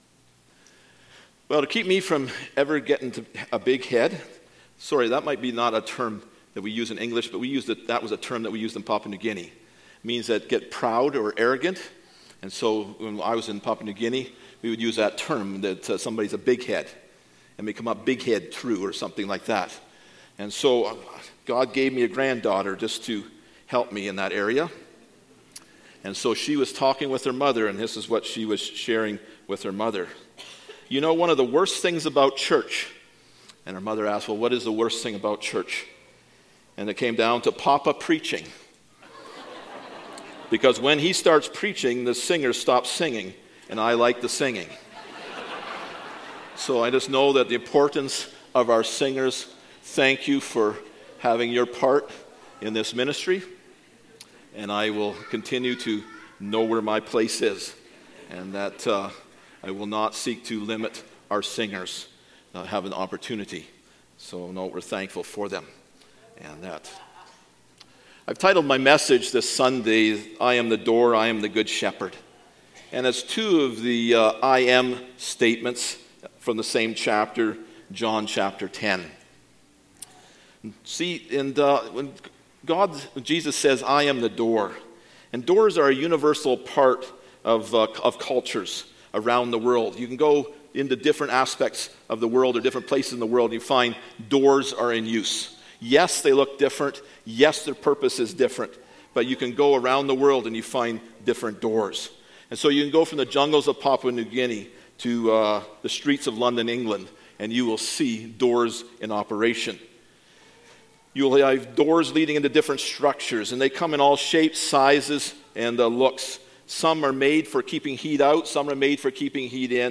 Gospel of John Passage: John 10:1-21 Service Type: Sunday Morning « I Am